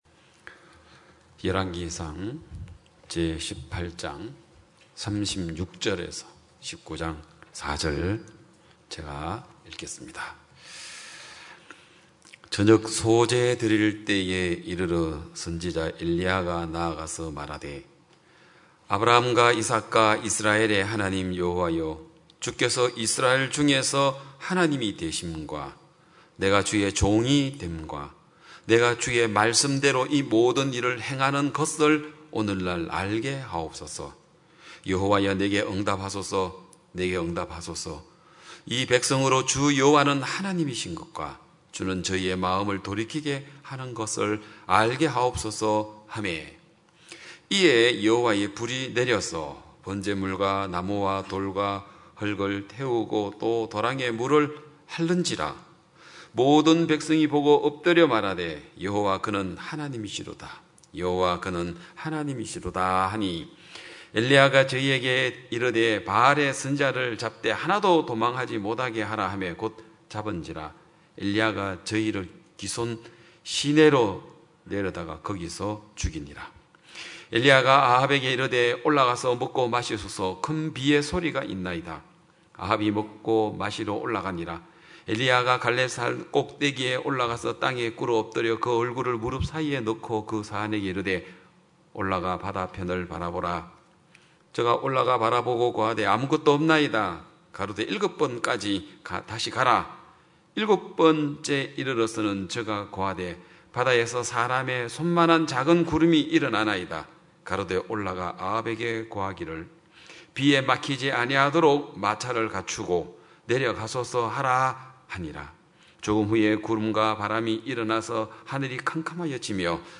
2022년 7월 17일 기쁜소식양천교회 주일오전예배
성도들이 모두 교회에 모여 말씀을 듣는 주일 예배의 설교는, 한 주간 우리 마음을 채웠던 생각을 내려두고 하나님의 말씀으로 가득 채우는 시간입니다.